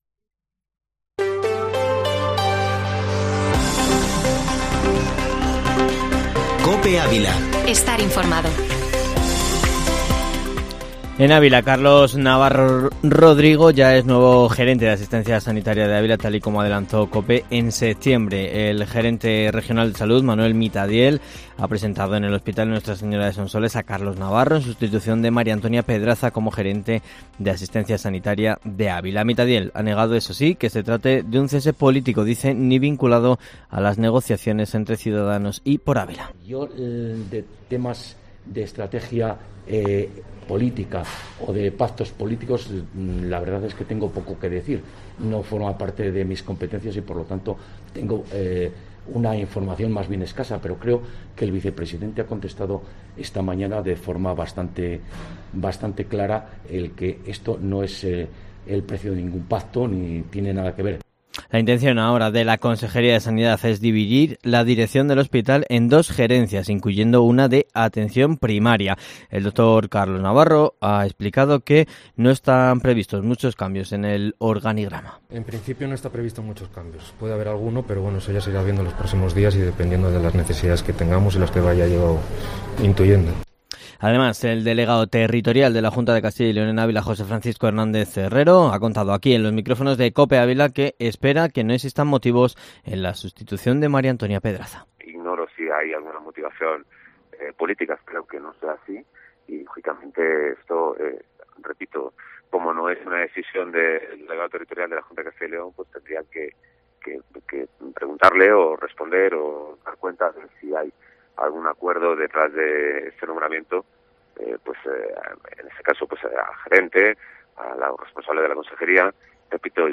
Informativo Matinal Herrera en COPE Ávila-19-oct